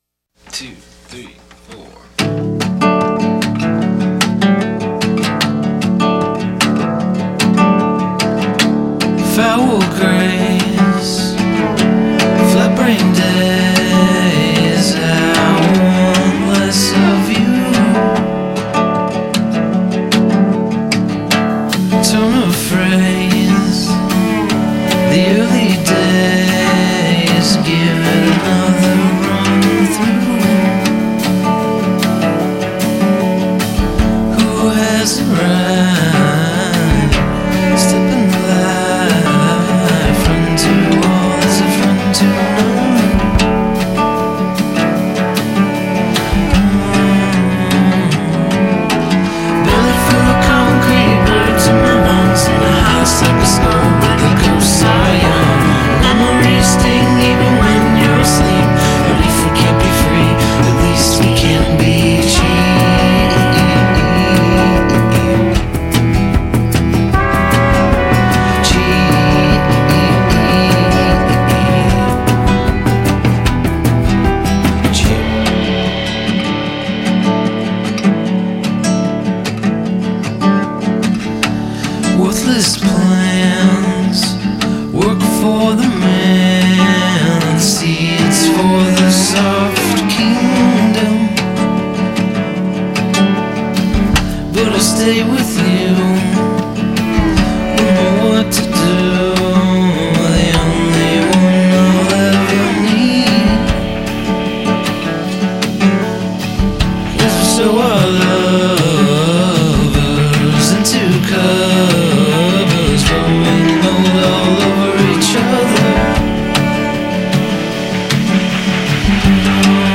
Folky, fuzzy and good.